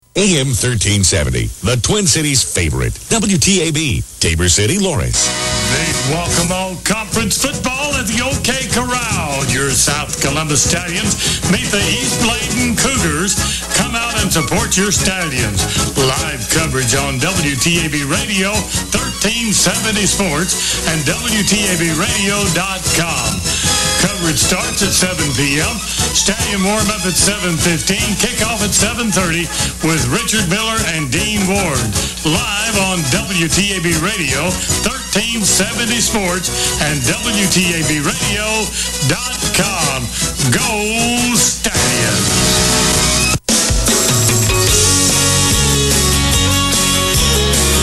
The station on 980 the other day was far too fast spoken for me.
111016_0700_980_xetu_radio_rama_stereo_vida_freq_ann.mp3